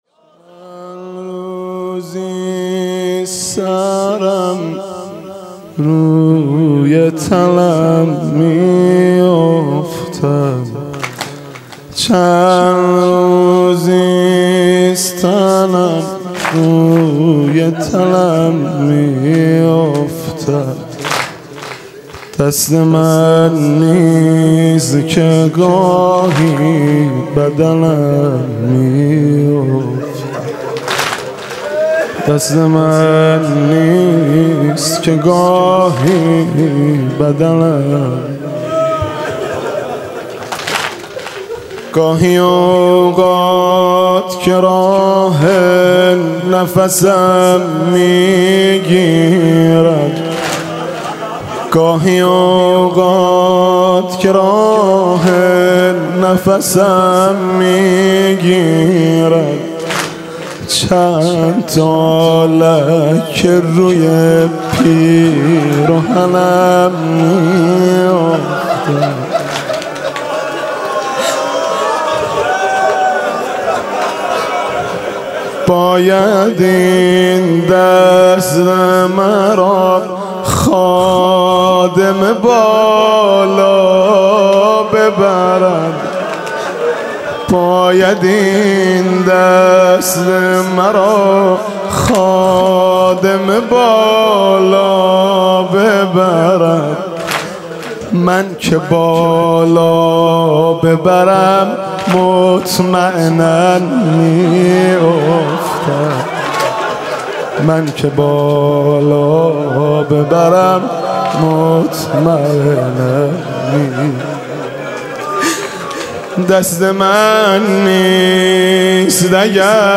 music-icon واحد: کاتب که خدا بود، قلم را به علی داد حاج مهدی رسولی